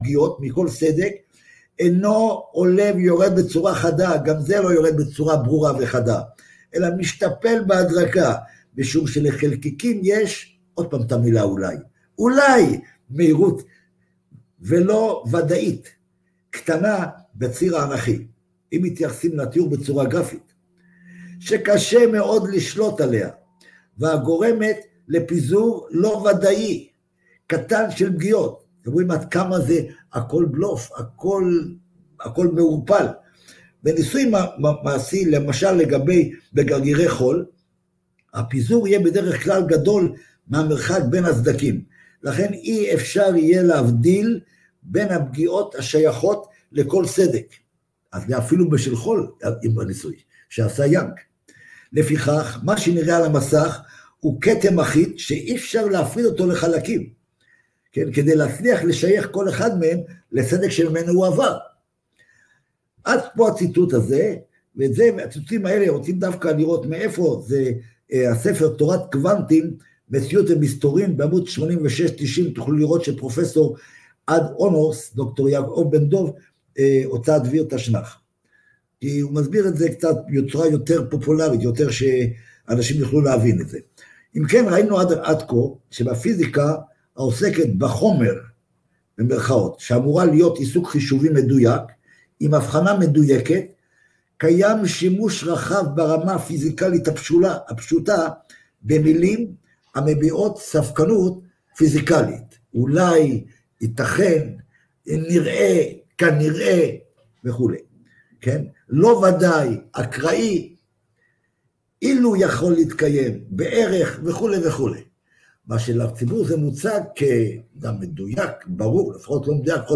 בסדרת הרצאות מרתקת המפריכה לחלוטין את תאוריית פיסקת הקוואנטים